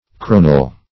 cronel \cro"nel\ (kr[=o]"n[e^]l), n. [Cf. Coronel spearhead,